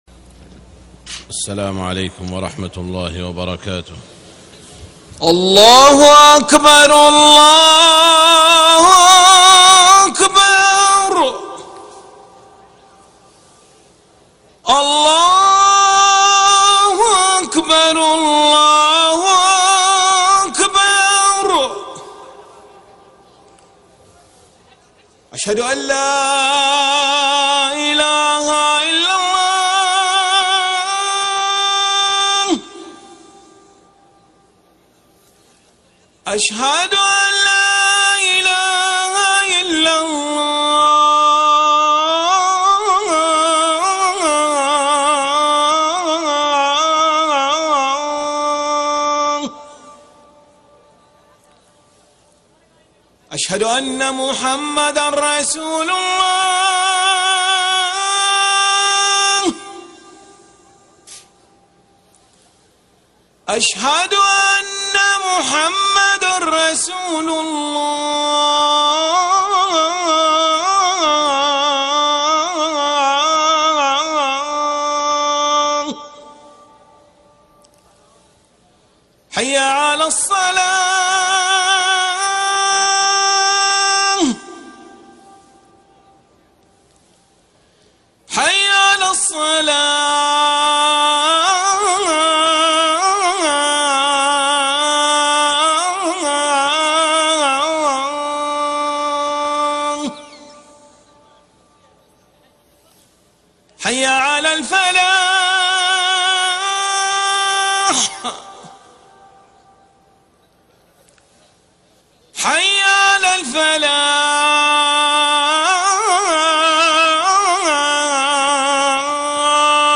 خطبة الجمعة 15 رمضان 1433هـ > خطب الحرم المكي عام 1433 🕋 > خطب الحرم المكي 🕋 > المزيد - تلاوات الحرمين